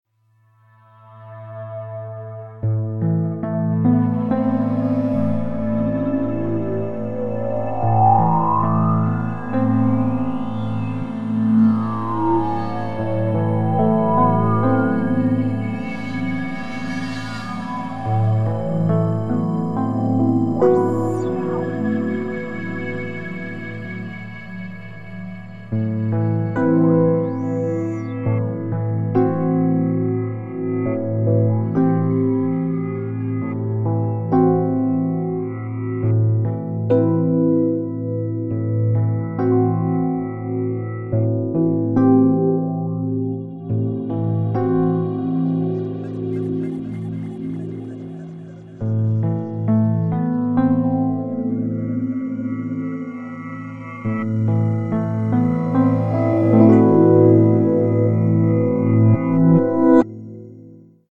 Electronic ambient